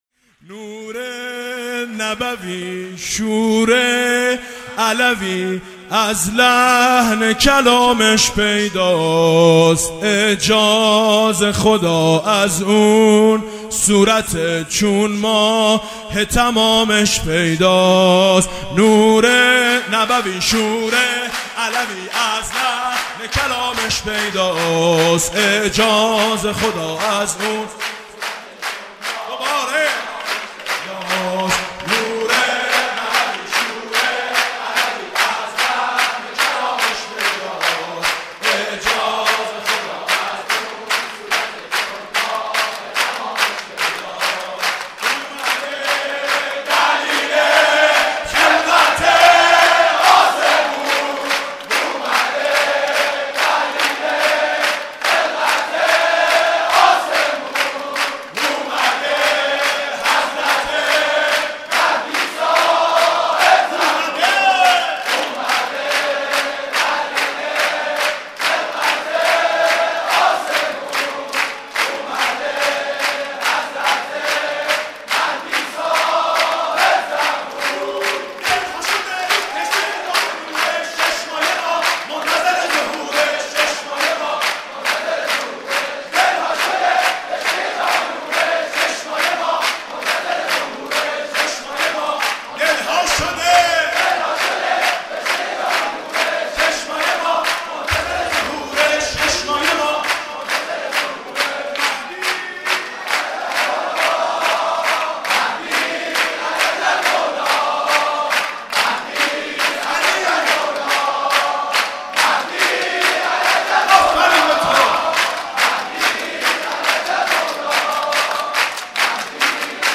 سرود: نور نبوی شور علوی